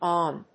/ˈɔːn(米国英語)/